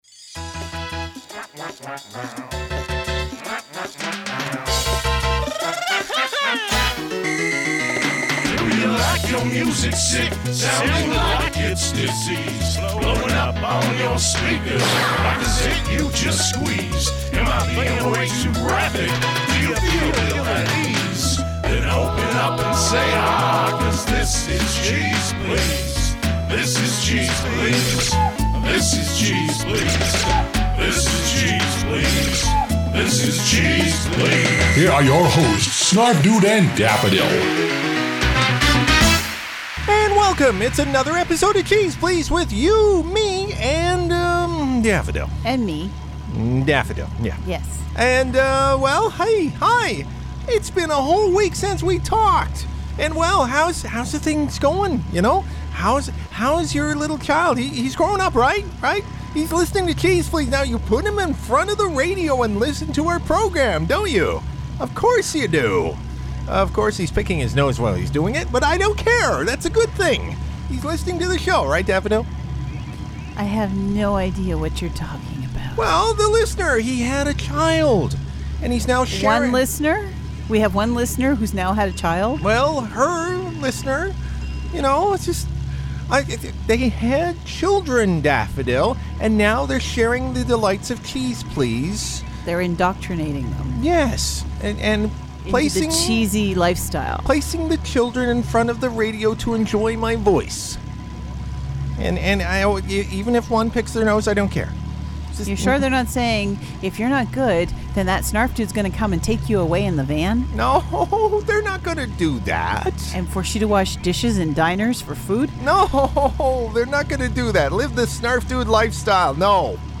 They're back to normal it seems...as our hosts dig out some ****head cheeze (they can't say it)